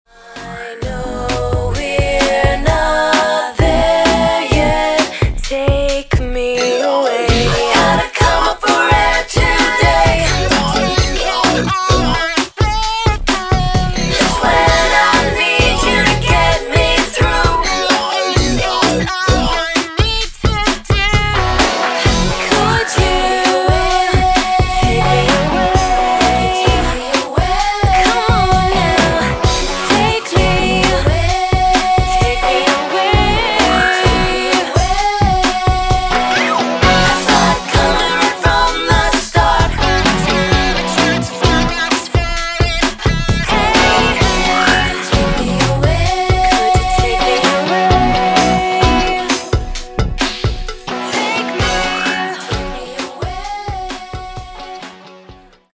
pop artist
With strong rhythms and
energetic power-pop tunes